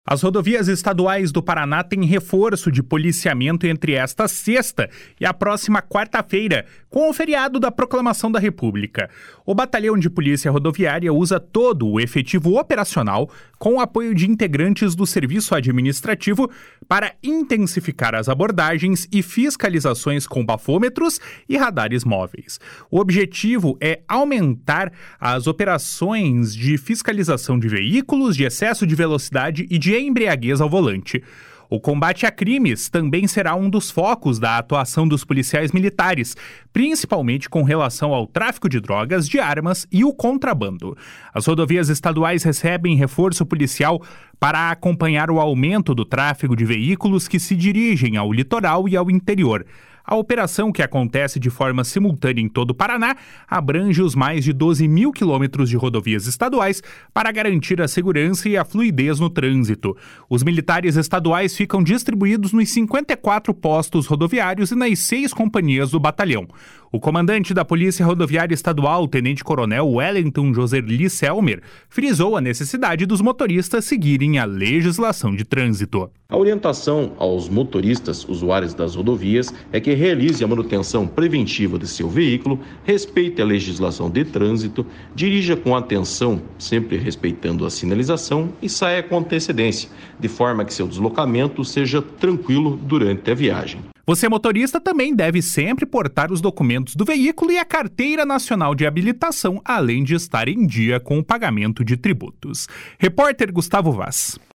O comandante da POlícia Rodoviária Estadual, tenente-coronel Wellenton Joserli Selmer, frisou a necessidade dos motoristas seguirem a legislação de trânsito.